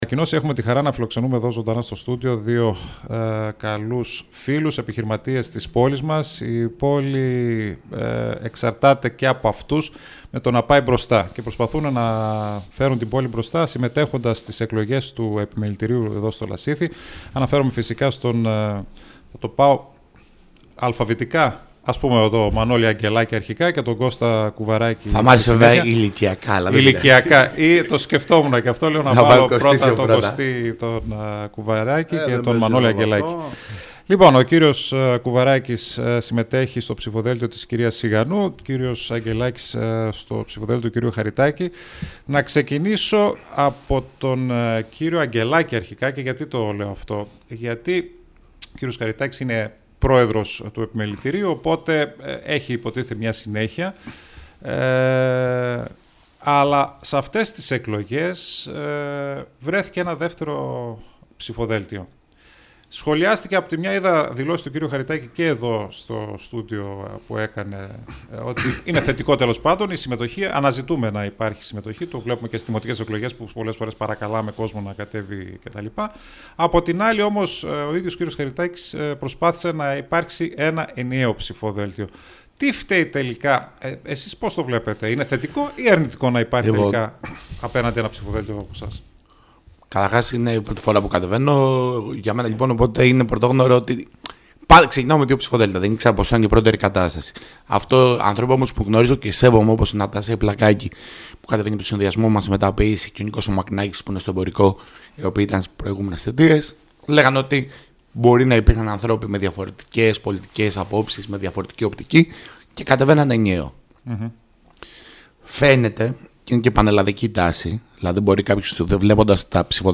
ΣΥΝΕΝΤΕΥΞΕΙΣ